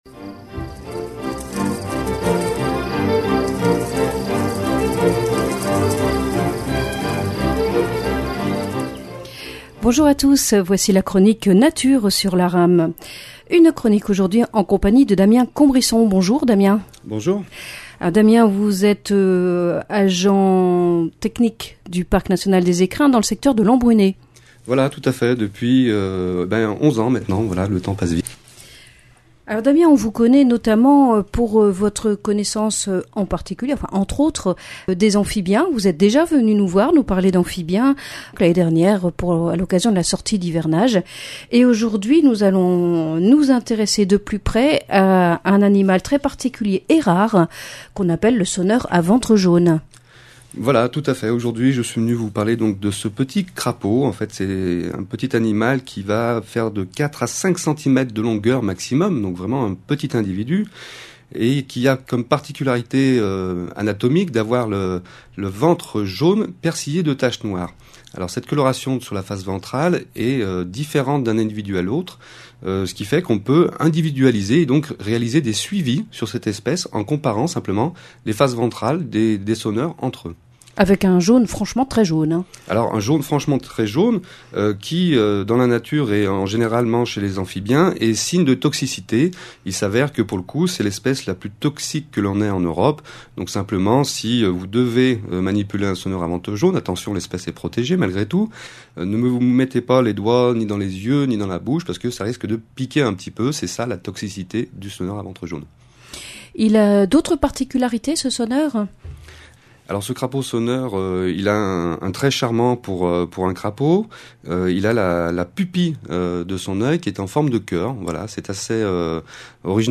Chronique nature Ce petit crapaud au ventre jaune persillé de tâches noires est potentiellement un charmeur à la pupille de l'œil en forme de cœur.